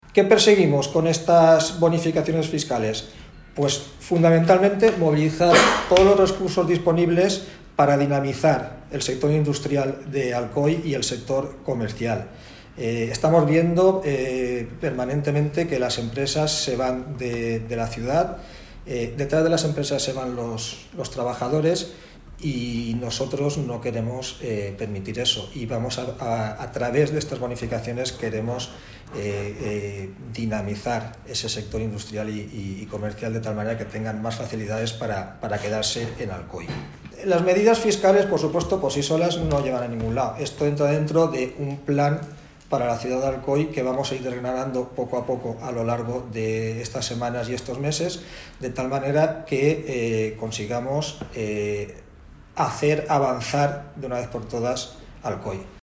Alcoy 9 de marzo de 2023 El Partido Popular de Alcoy ha presentado en rueda de prensa la primera de sus propuestas incluidas en el programa electoral para las próximas elecciones municipales.